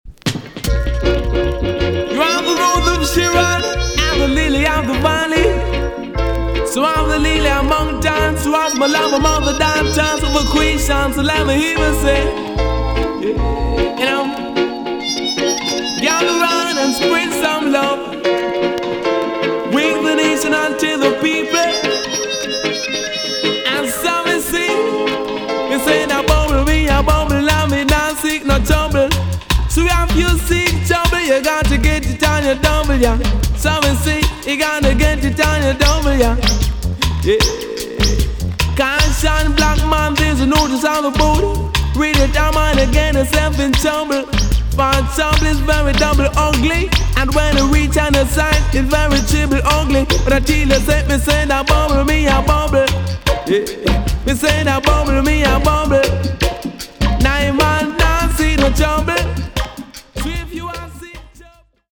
EX-~VG+ 少し軽いチリノイズがありますがキレイです。